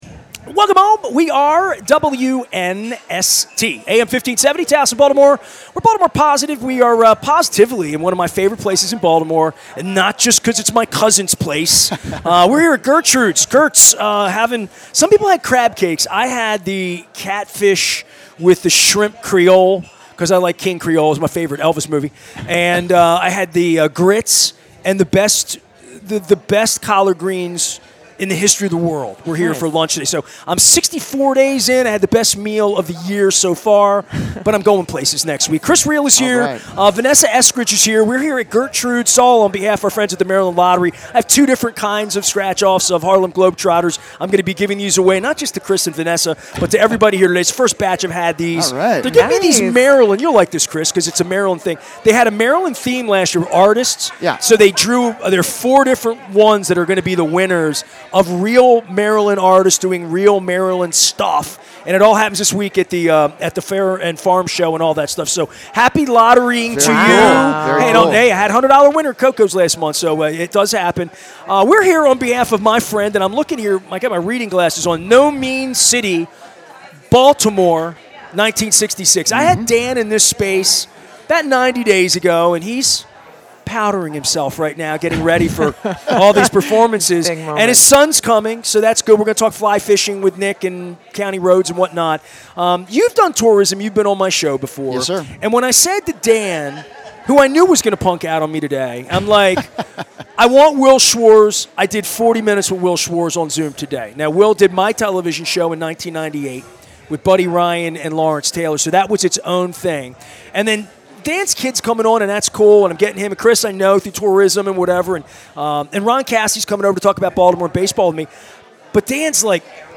for a Maryland Crab Cake Tour stop